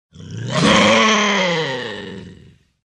Звуки льва, тигра и кошек
Рысь 2